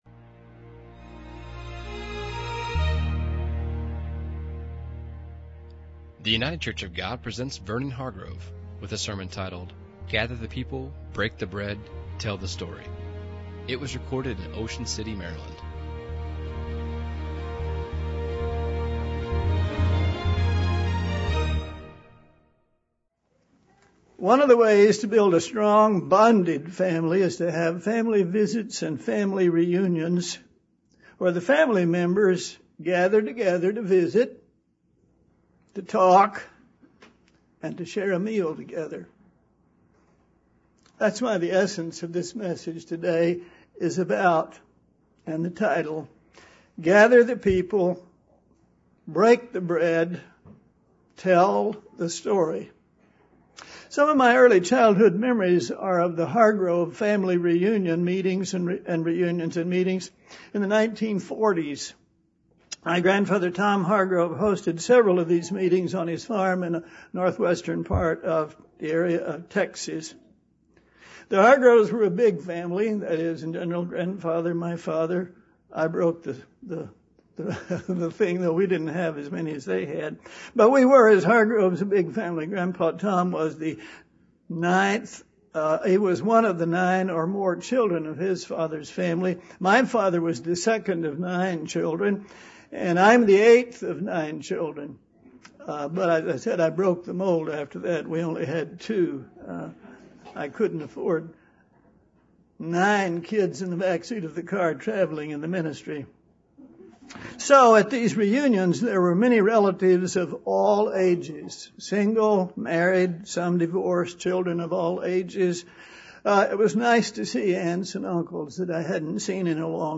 This sermon was given at the Ocean City, Maryland 2013 Feast site.